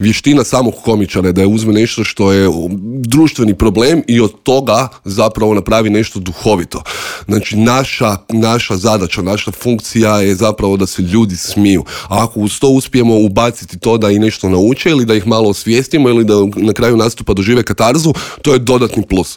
ZAGREB - Novu sezonu intervjua na Media servisu otvorili smo laganom temom.